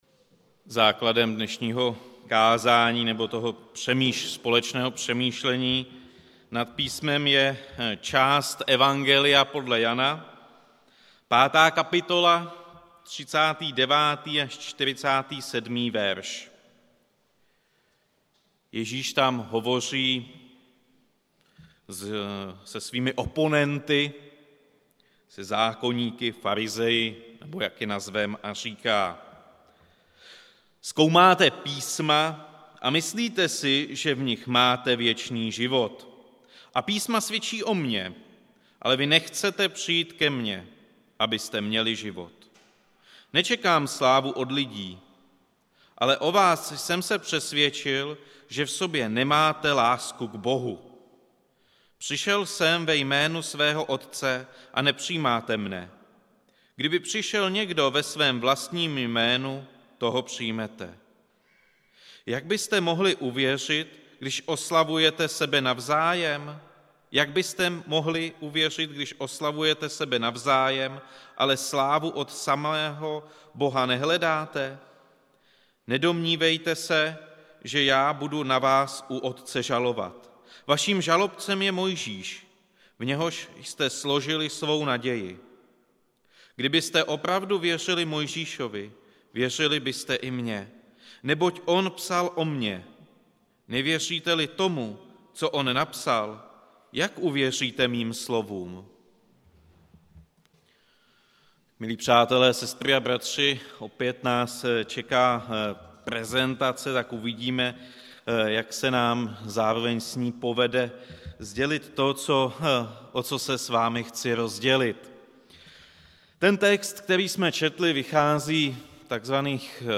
Událost: Kázání